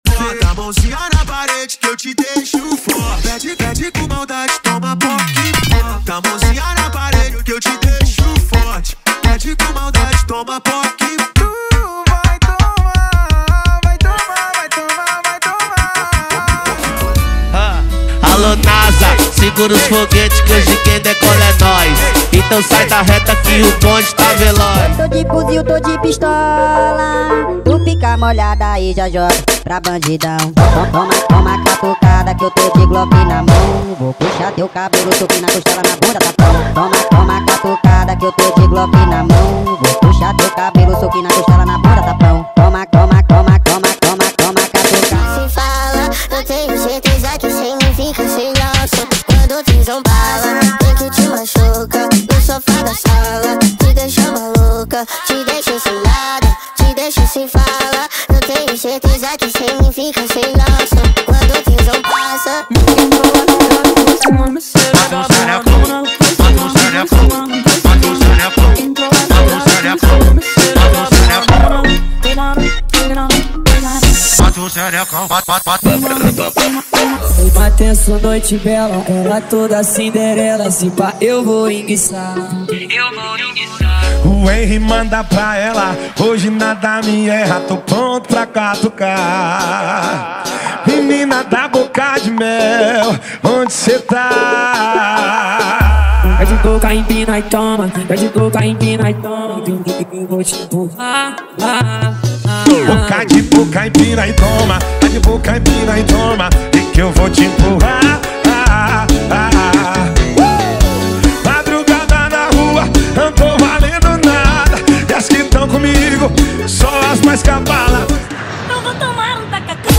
• Brega Funk = 50 Músicas
• Sem Vinhetas
• Em Alta Qualidade